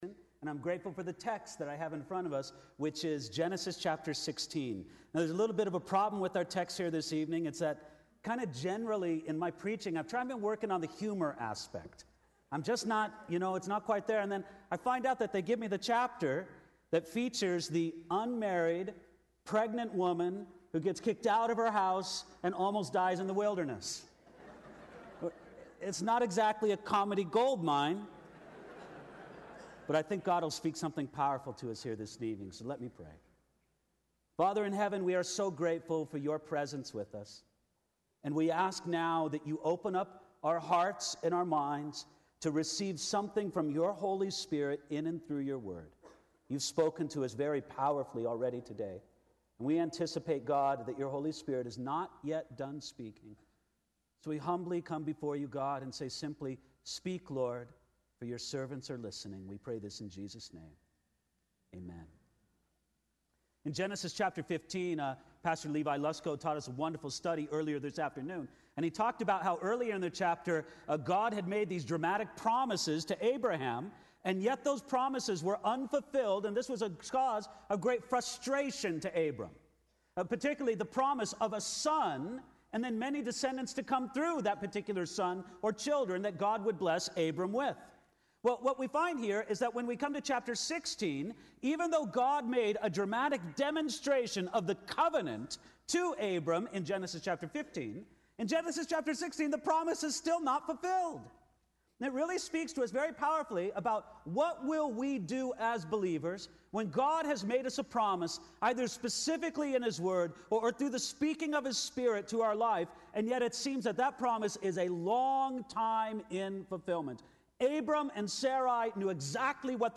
2015 SW Pastors and Leaders Conference